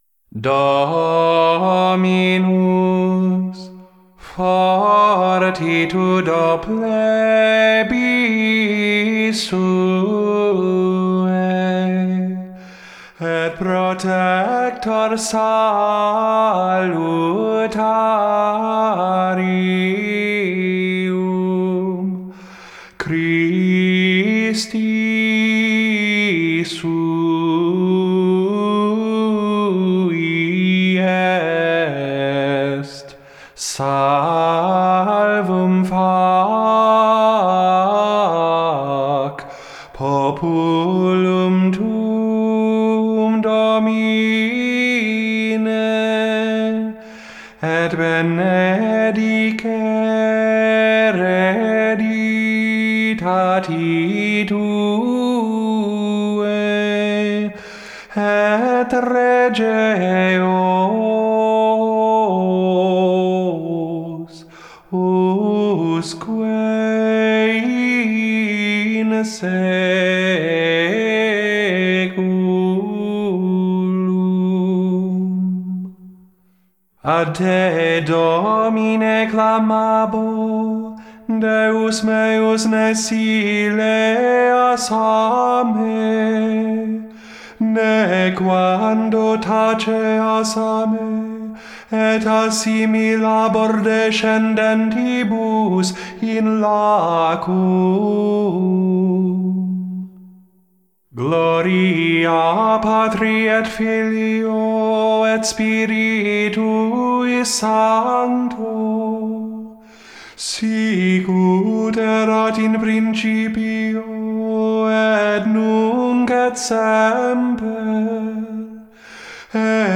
4740 INTROIT 6th Sunday after Pentecost